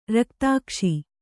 ♪ raktākṣi